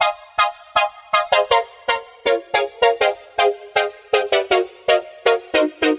描述：这些是由我重新制作的卡哇伊和弦
Tag: 160 bpm Electro Loops Synth Loops 1.01 MB wav Key : Unknown